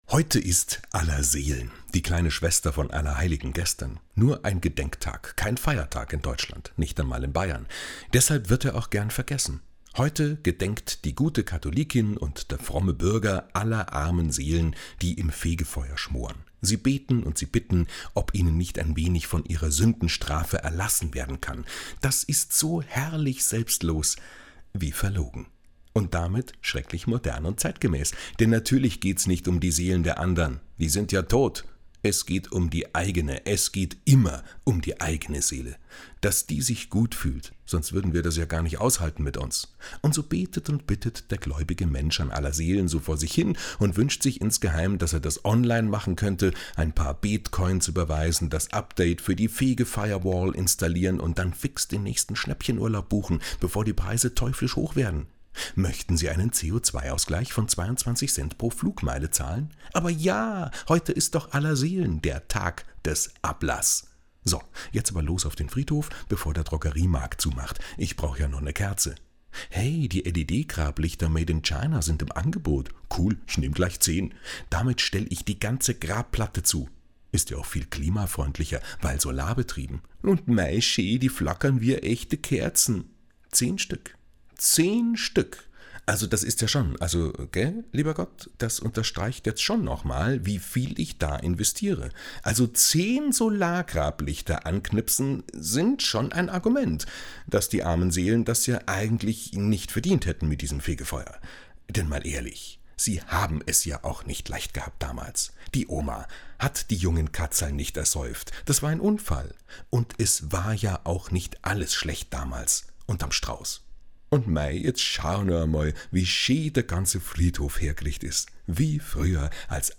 tägliche Glosse von wechselnden Autor*innen, Bayern 2 Radiowelt